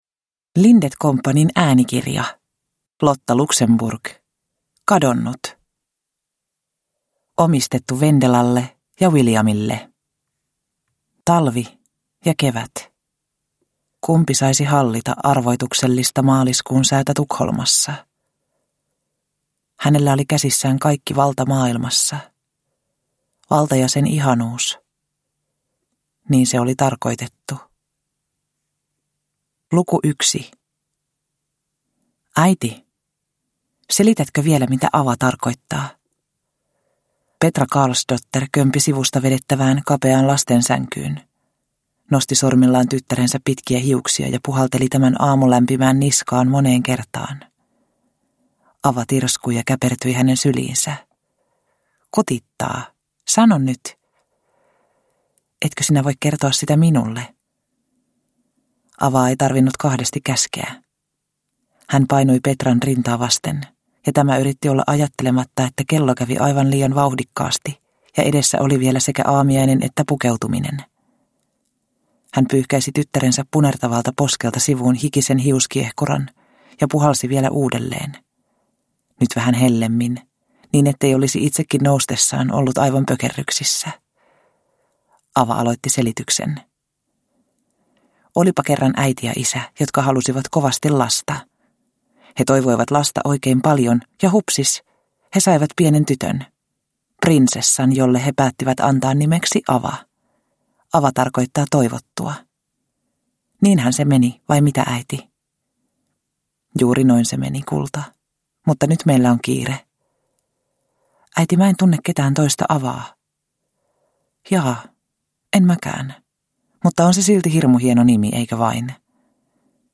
Kadonnut – Ljudbok – Laddas ner